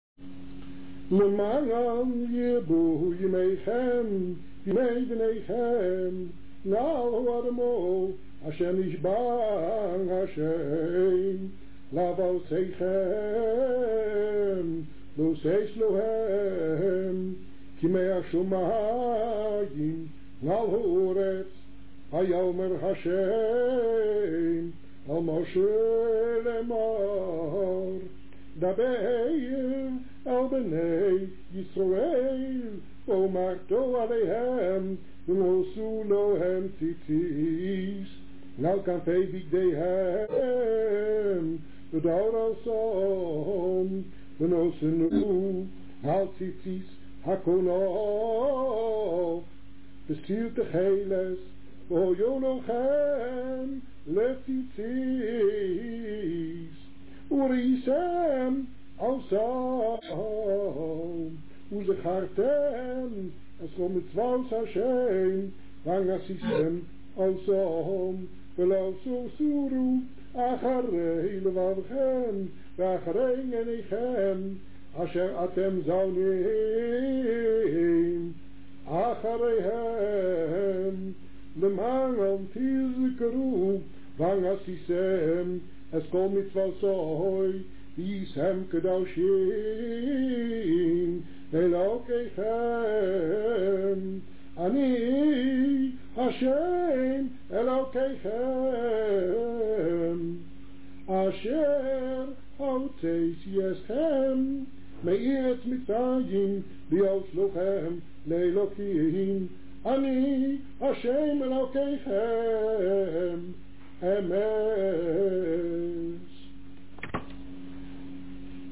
Opnames opperrabbijn Aharon Schuster
שמע Door opperrabbijn gewoon &